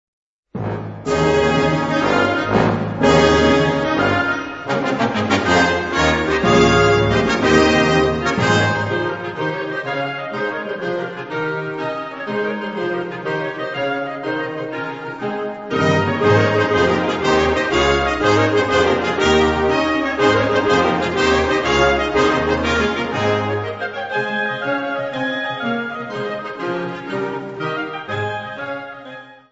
Catégorie Harmonie/Fanfare/Brass-band